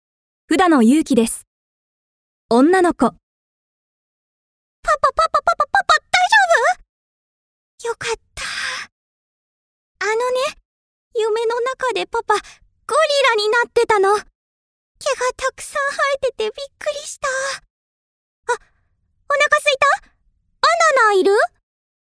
ナレーション
すっきり聞きなじみのいい優しい声で、 作品を彩る表現をお届けします！